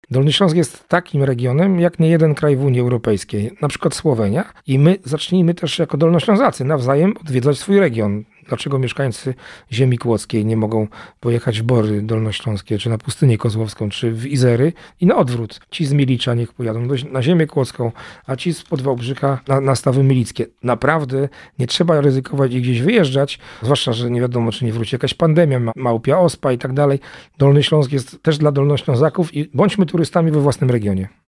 Krzysztof Maj – Członek Zarządu Województwa apeluje o poznawanie swojego regionu.